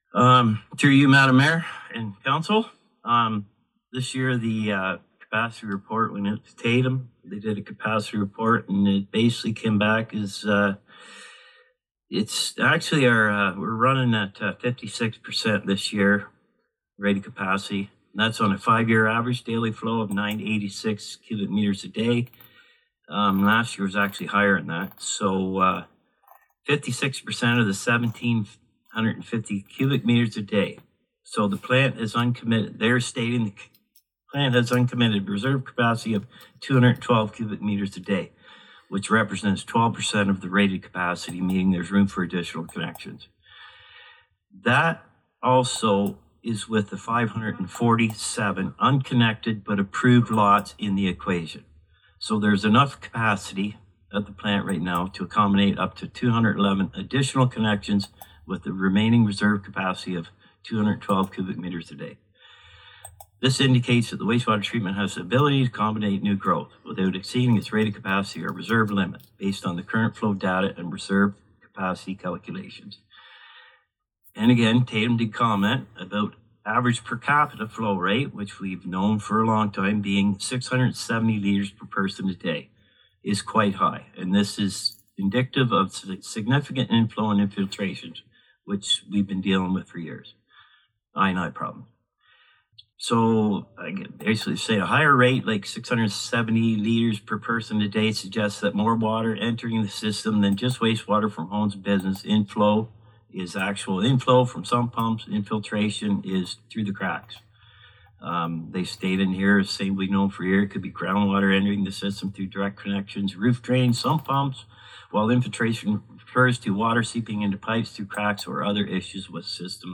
In Their Words: Sump pumps place pressure on water treatment, says environmental manager in report to council - Consider This